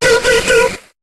Cri de Démanta dans Pokémon HOME.